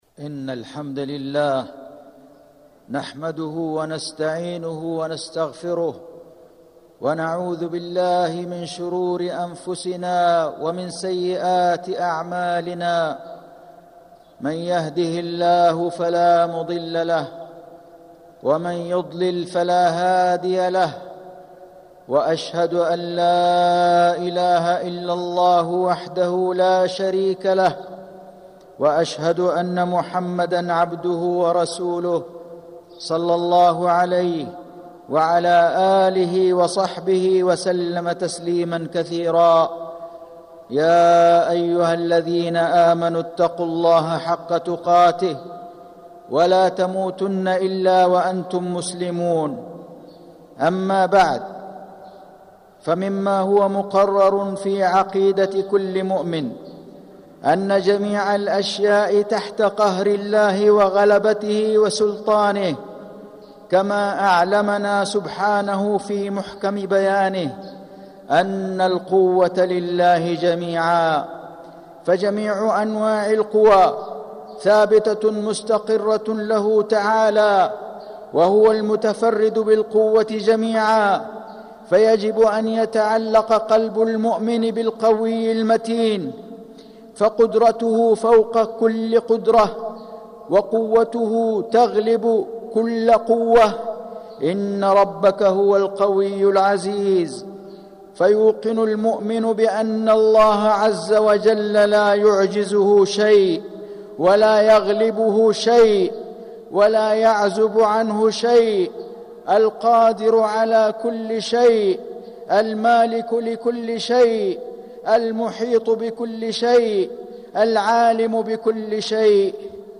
مكة: تعلق القلب بربنا القوي المتين - فيصل بن جميل غزاوي (صوت - جودة عالية